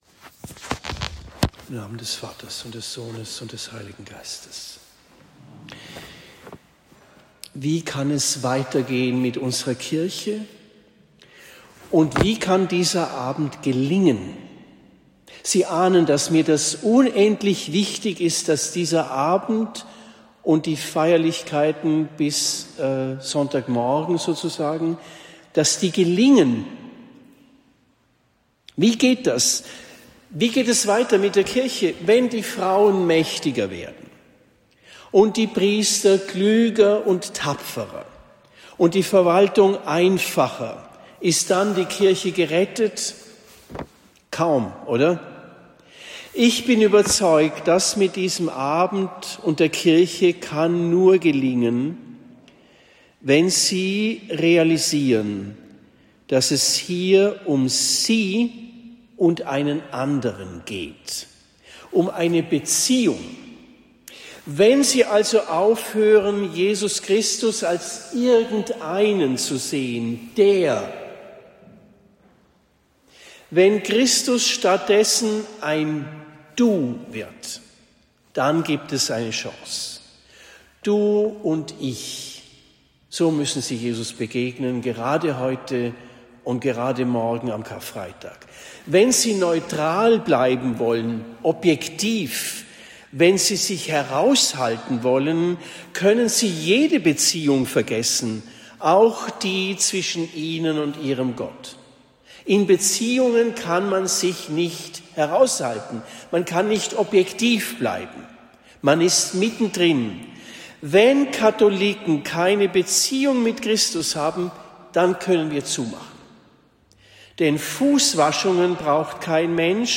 Gründonnerstag 2026 Predigt in Lengfurt am 02. April 2026